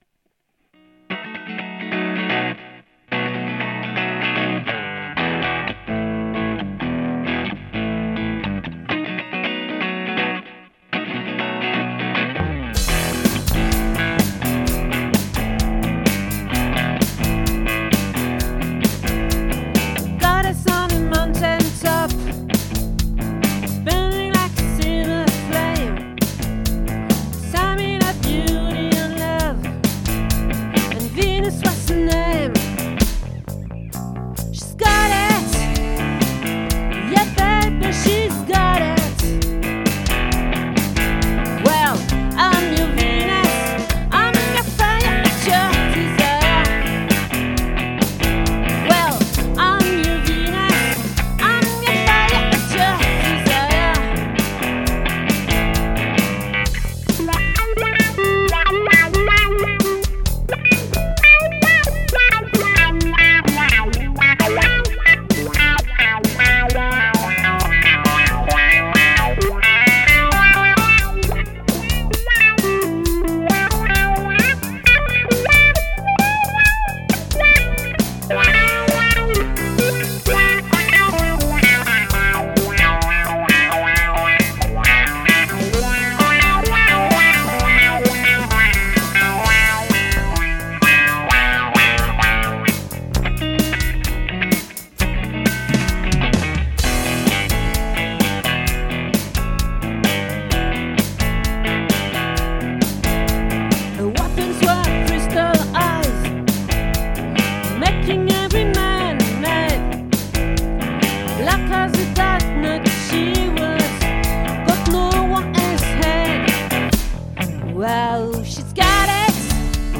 🏠 Accueil Repetitions Records_2022_01_19